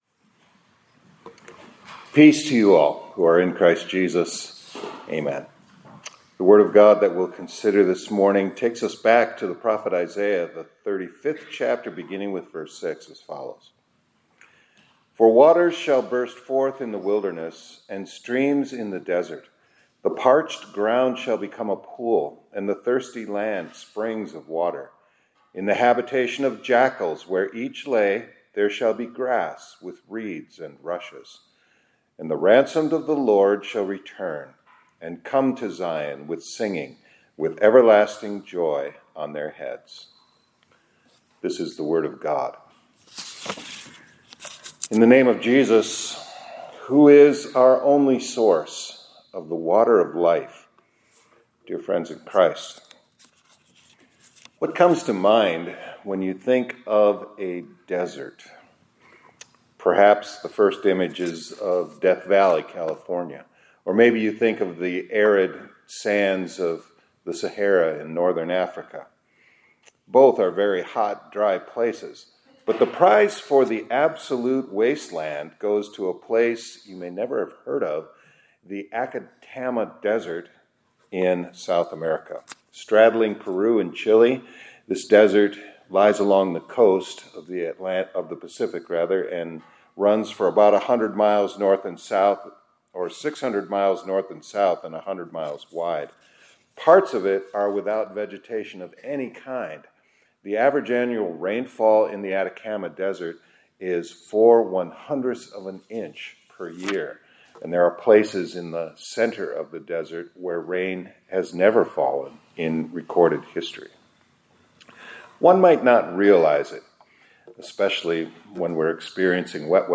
2024-05-14 ILC Chapel — The Unbelievable Parable of the…